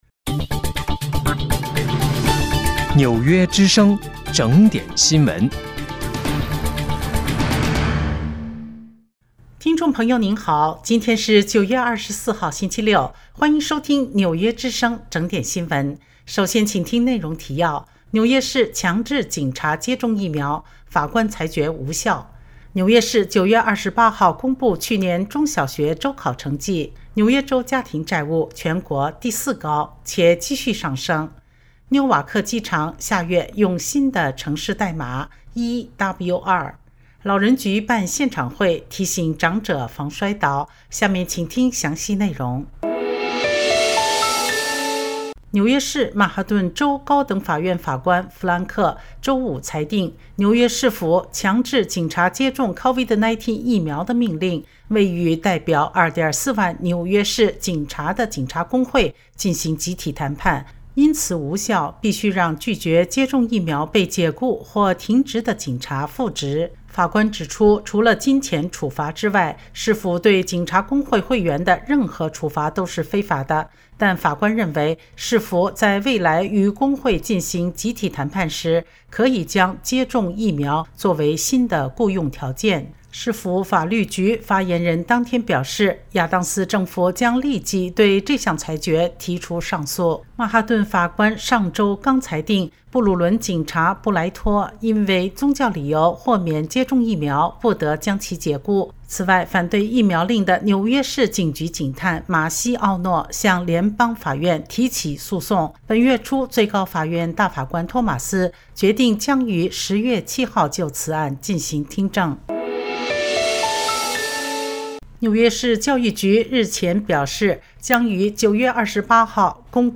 9月24号(星期六)纽约整点新闻